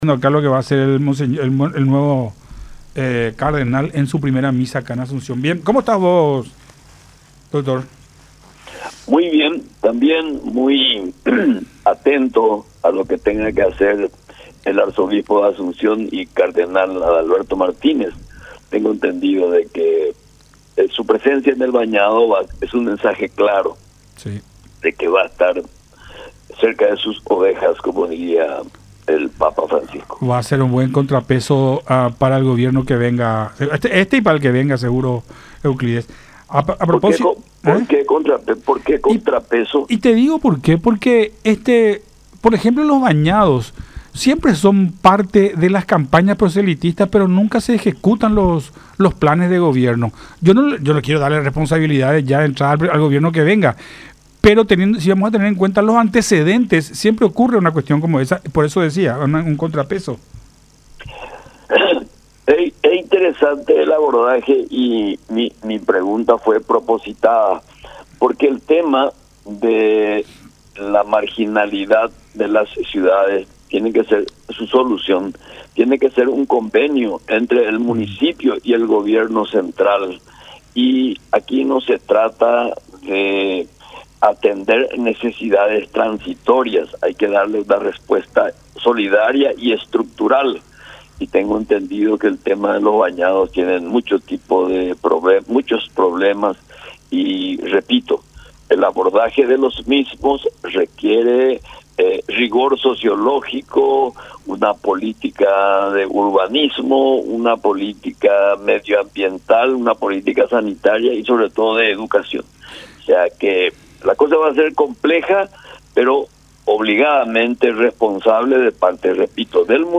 “Nuestra política electoral seguirá siendo la misma desde el inicio: una conexión permanente con la ciudadanía, con la que uno conversa, debe escuchar y, por sobre todas las cosas, decirles que nosotros estamos por la ‘refundación’ de la República”, expresó Acevedo en diálogo con Nuestra Mañana a través de Unión TV y radio La Unión.